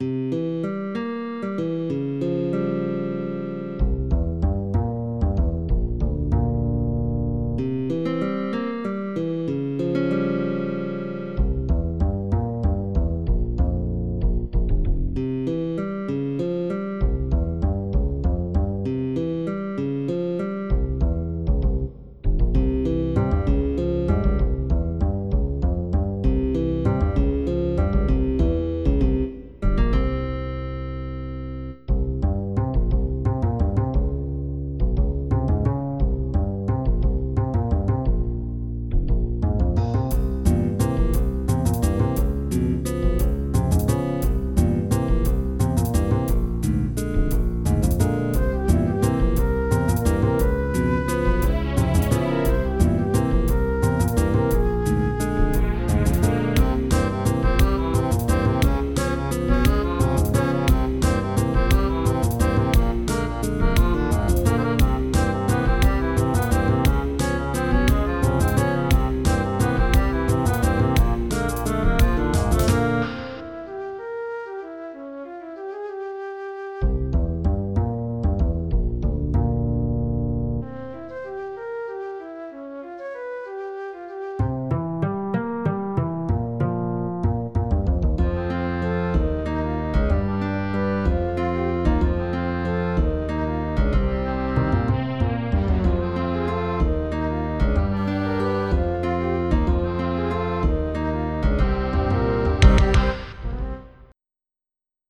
Does the flute represents the bird?
LATIN POP MUSIC